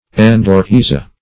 Endorhiza \En`do*rhi"za\, n.; pl. Endorhiz[ae]. [NL., fr. Gr.